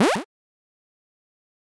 hit.ogg